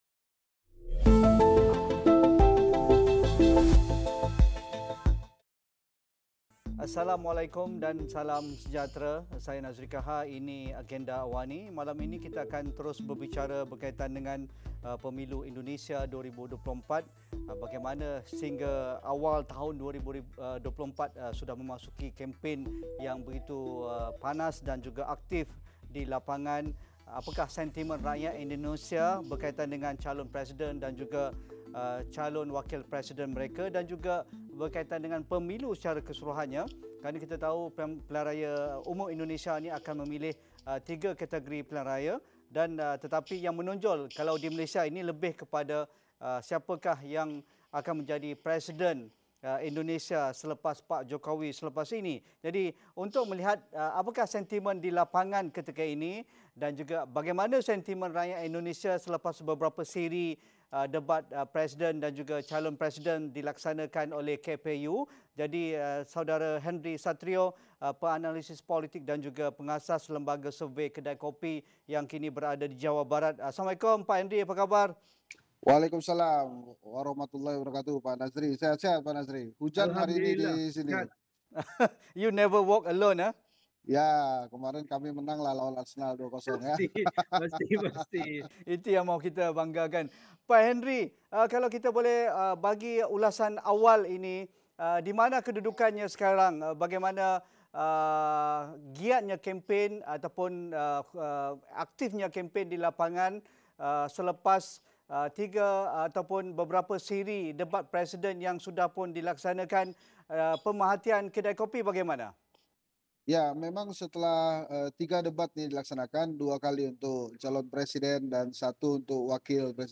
Apa yang mencorak sentimen pengundi menjelang Pemilihan Umum Indonesia (PEMILU) 2024 ketika perhatian lebih terarah kepada pengaruh dan prestasi calon presiden dan wakil presiden berbanding isu dan gagasan idea yang mahu diperjuangkan? Diskusi 9 malam